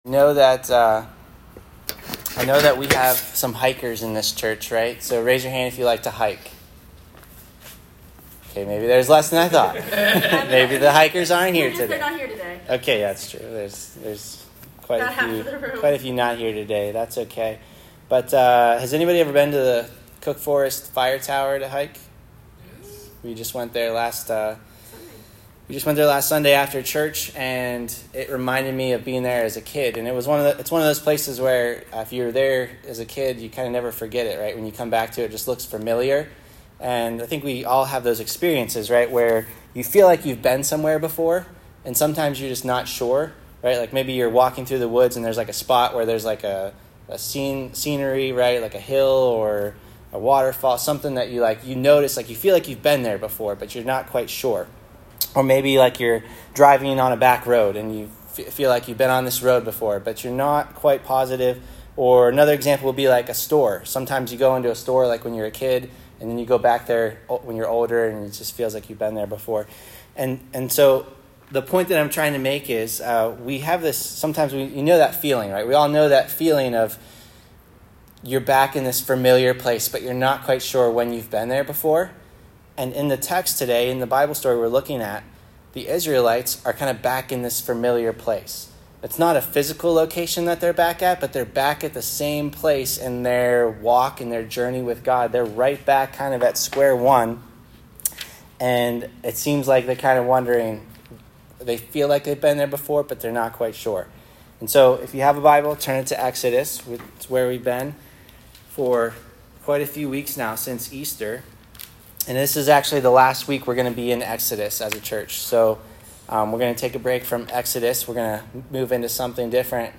God Has A Name Current Sermon